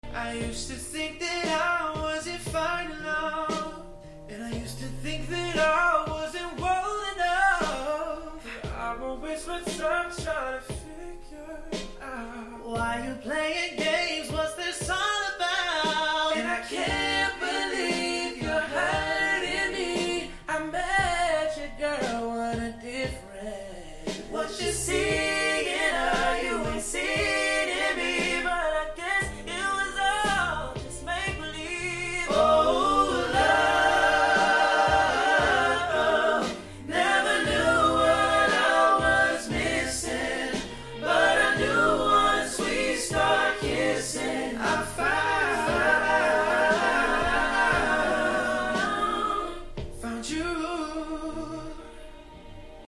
had a great time singing with these guys!